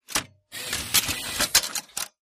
3 /4" Video tape deck tape loads and plays. Tape Loading Transport Engage Eject Tape